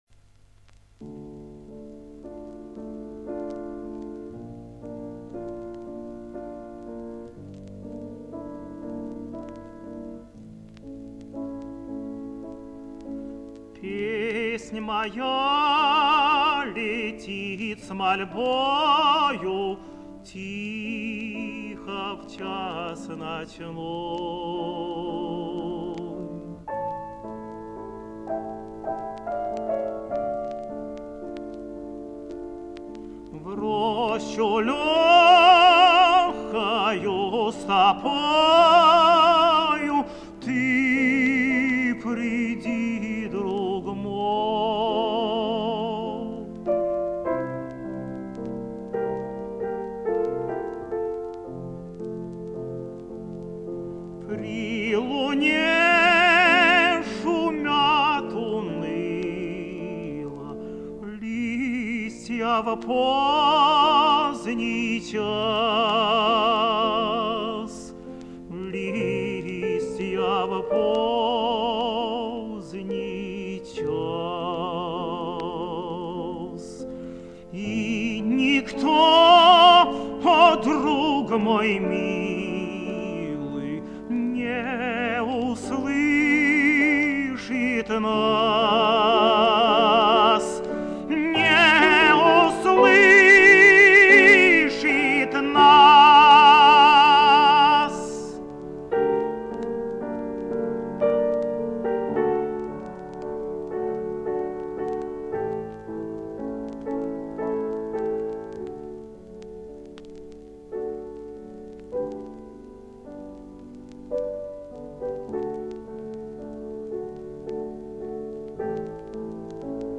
«Пе-еснь моя-я-я... лети-ит с мольбо-о-о-ю» – «Вечерняя серенада» Ф. Шуберта на стихи Л. Рельштаба, русский перевод Н.П. Огарева.
"Вечерняя серенада" в исполнении И. Козловского